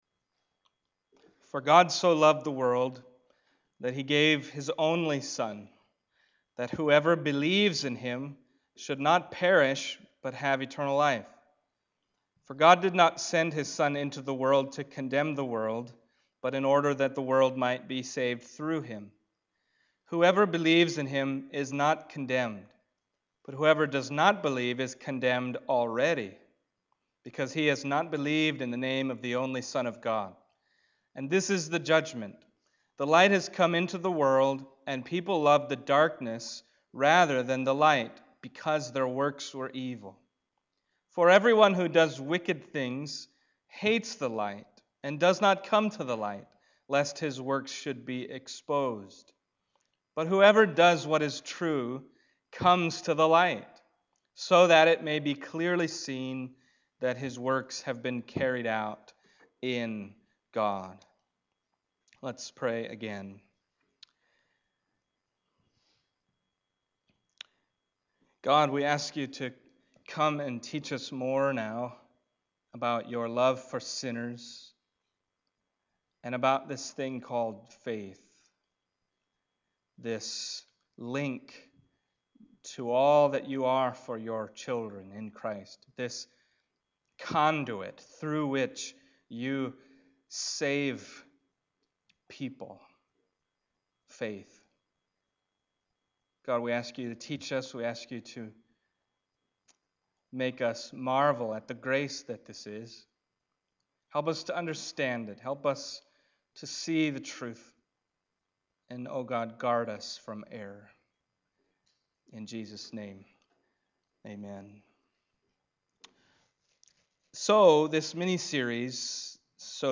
John Passage: John 3:16-21 Service Type: Sunday Morning John 3:16-21 « The Measure of God’s Love for Sinners Not All Believe in God’s Only Son …